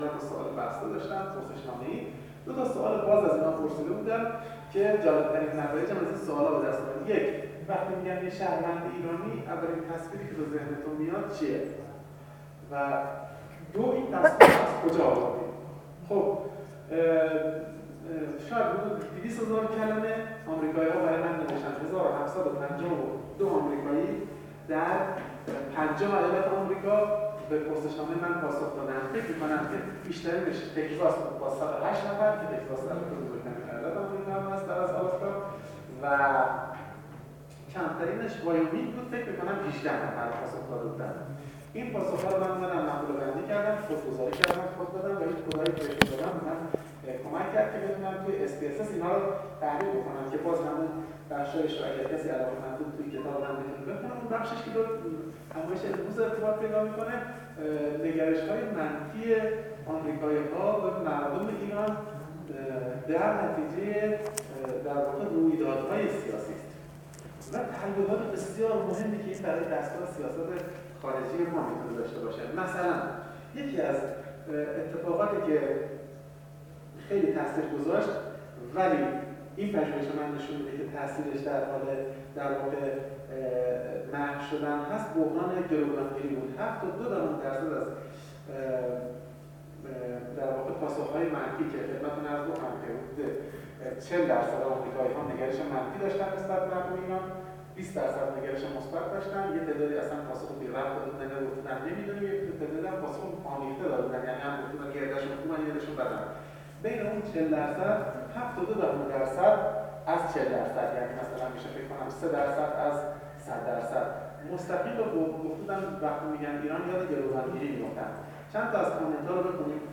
به گزارش خبرنگار ایکنا، دومین بخش نشست بازتاب انقلاب اسلامی در آثار اندیشمندان جهانی، 17 بهمن ماه در دانشکده حقوق و علوم سیاسی دانشگاه تهران برگزار شد.